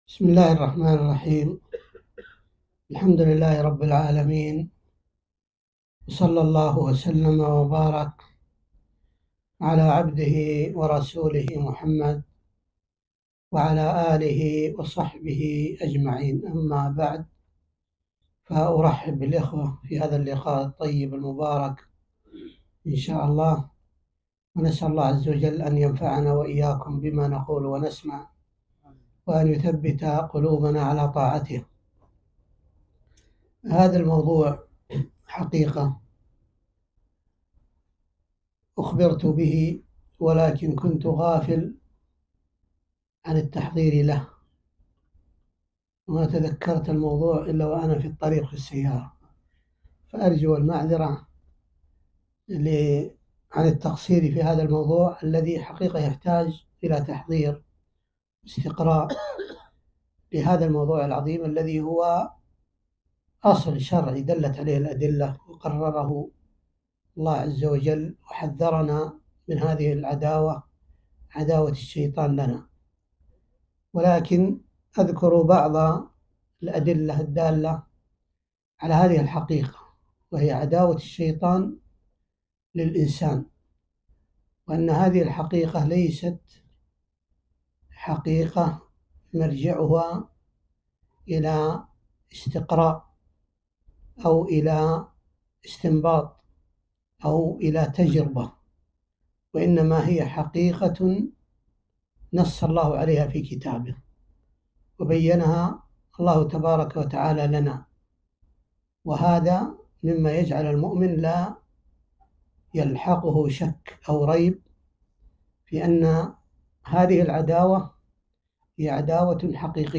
محاضرة - عداوة الشيطان للإنسان